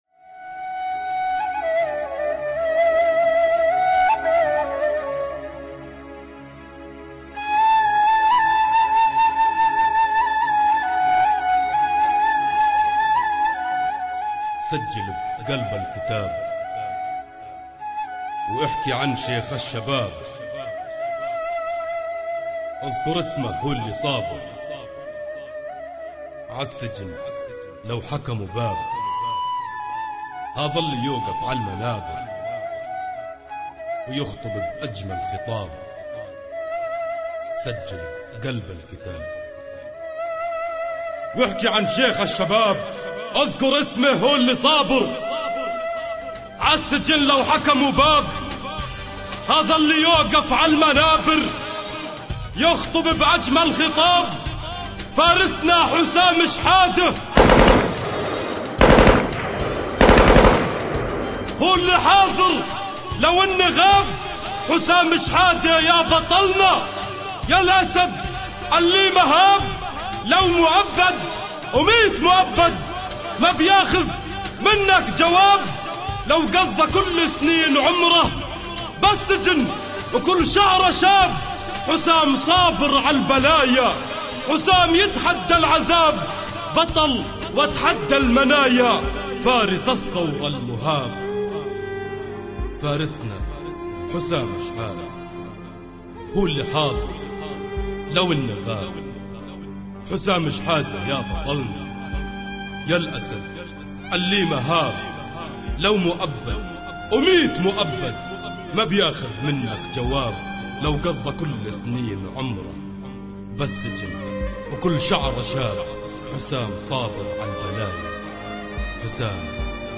اغاني فلسطينيه